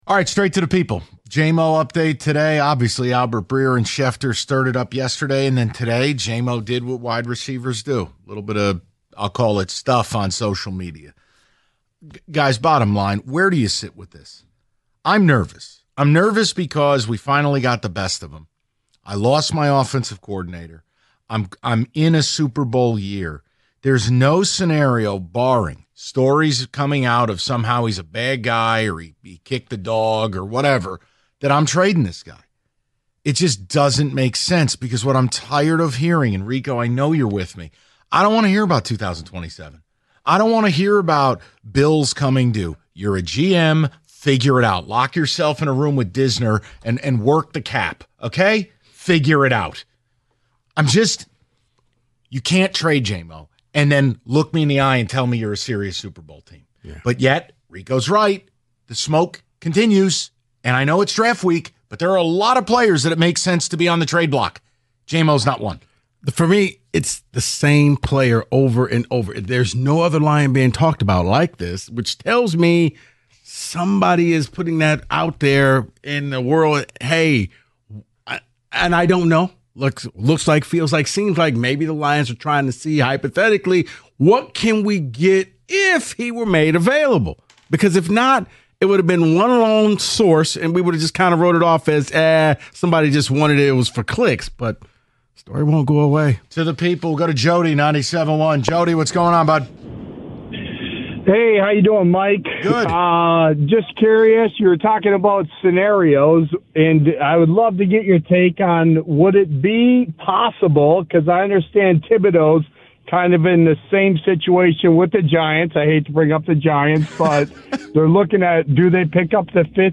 The guys take your calls on the Jamo discussion.